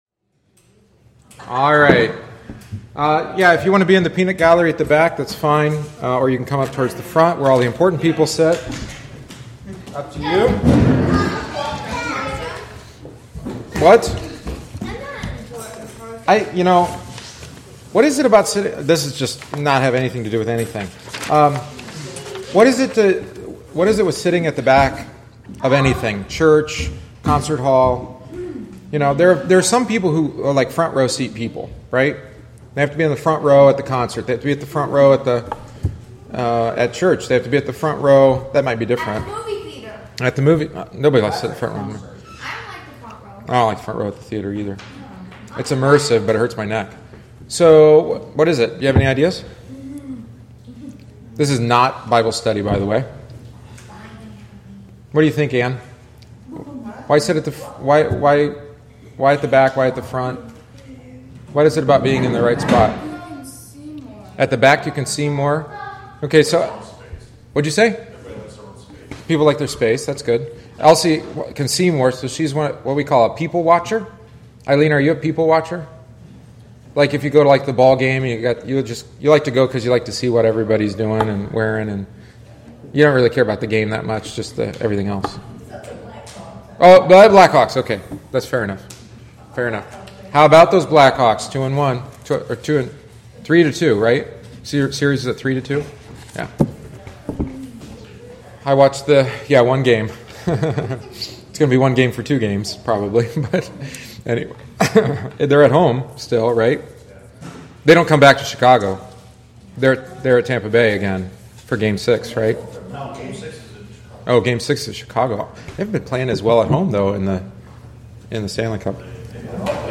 Bible Study for the Sunday of the Great Banquet 2015